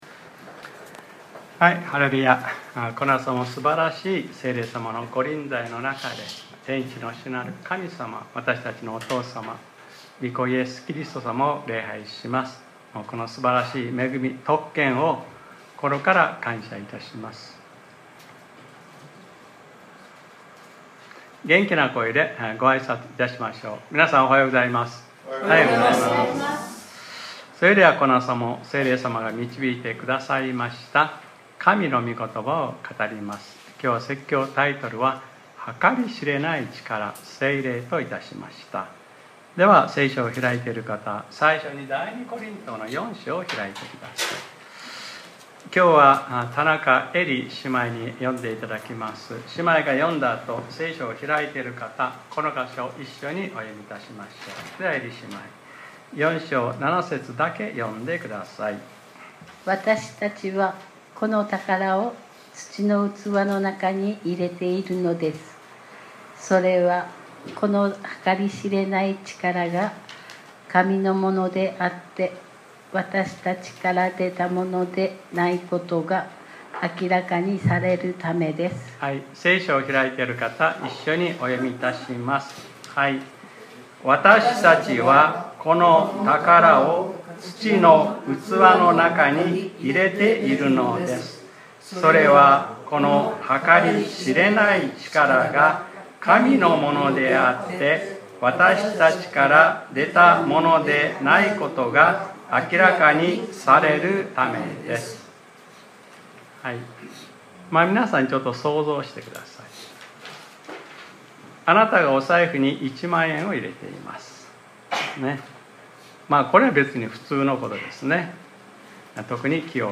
2025年07月27日（日）礼拝説教『 測り知れない力：聖霊 』